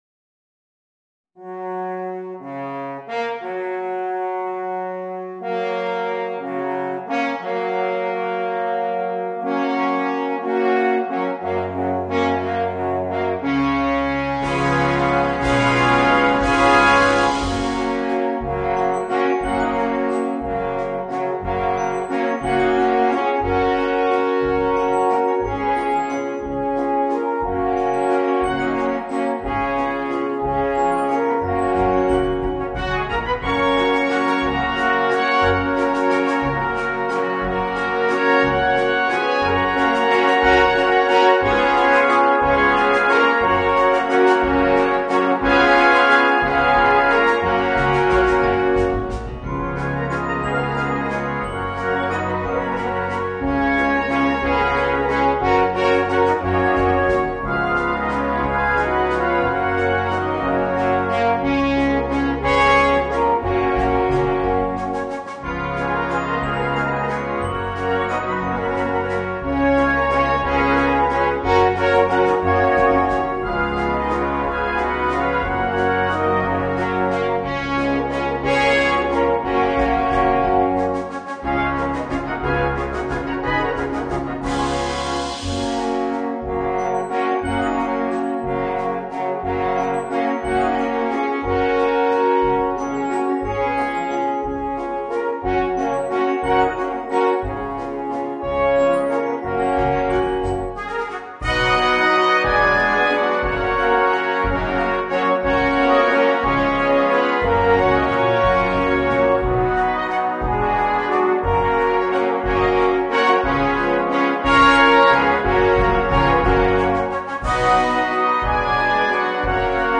Voicing: 2 Alphorns and Brass Band